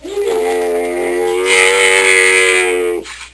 c_camel_bat2.wav